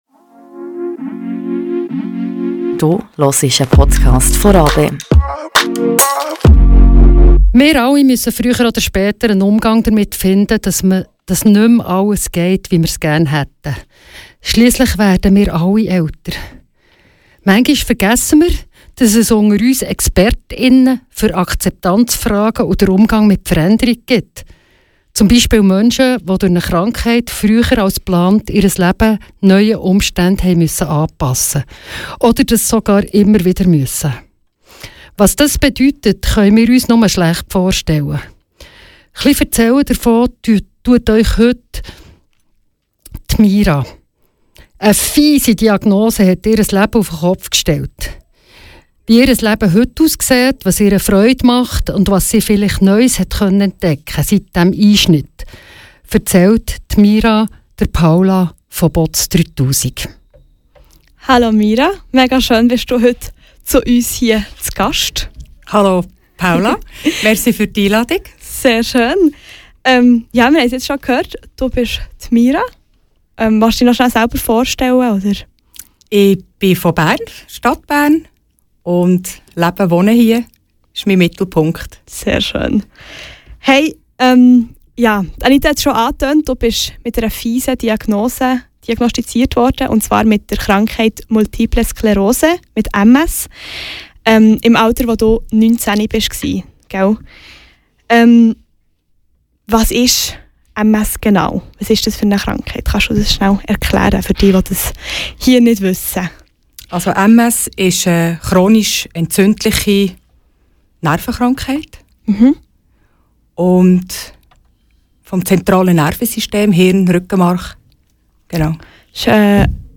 Live Interview